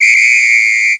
SE_WHISTLE.mp3